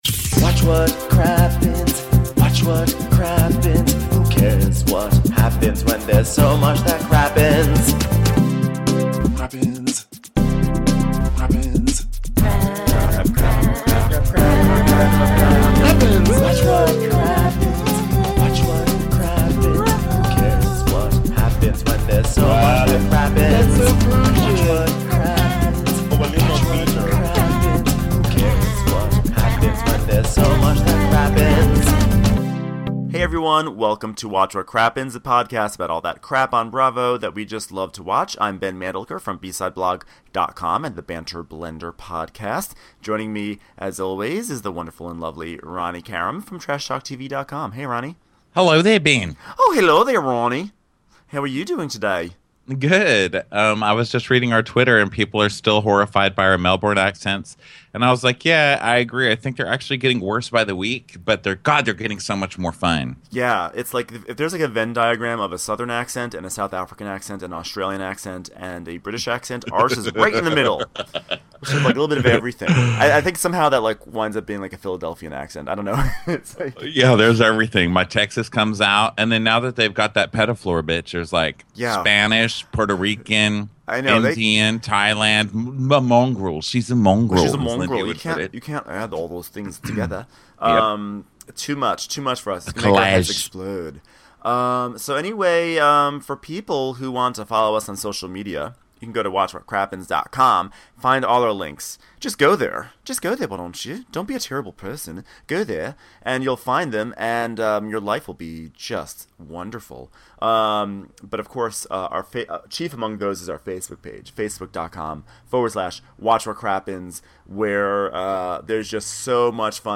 Buckle your seat belts because there's a lot of ranting coming your way (but in the most charming way).